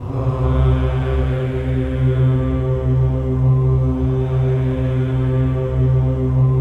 VOWEL MV01-L.wav